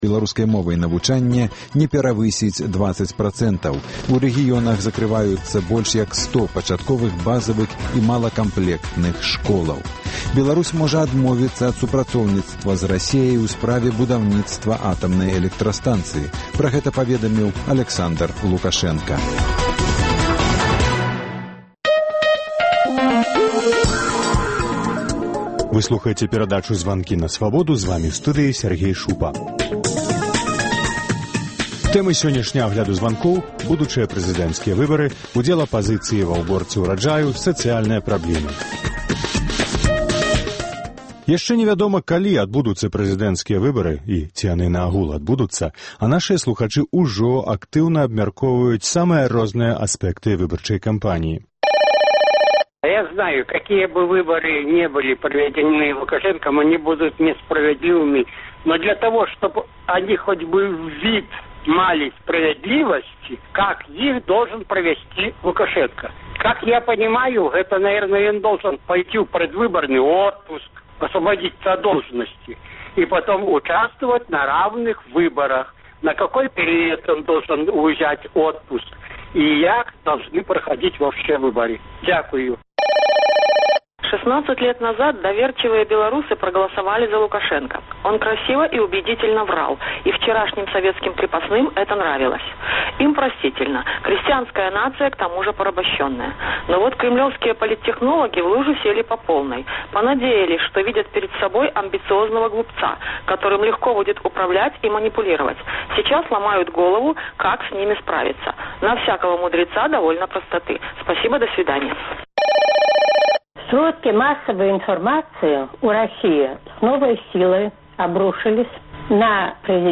Агляд тэлефанаваньняў за тыдзень